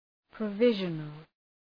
Προφορά
{prə’vıʒənəl}
provisional.mp3